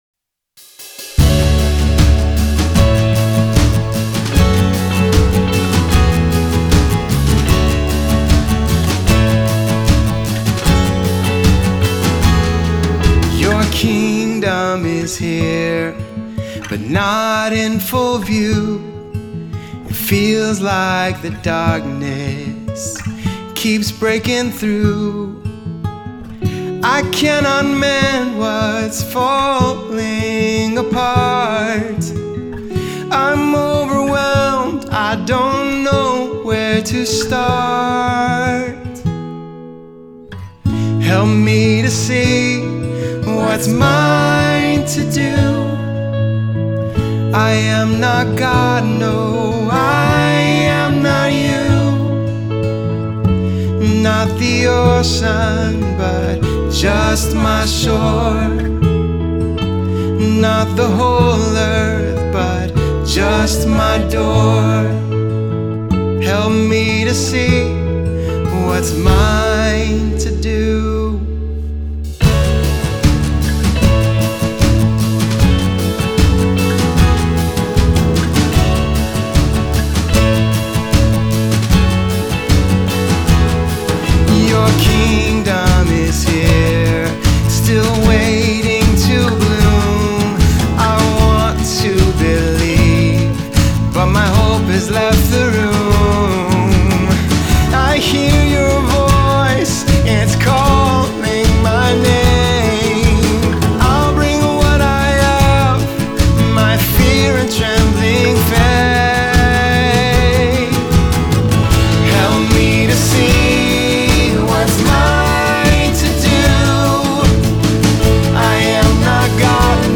Lead vocal
Background vocals